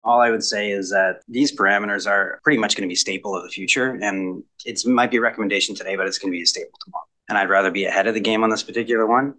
Sparks flew in Bluewater council chambers on Monday night (June 18) when councillors discussed the municipality’s alcohol policy.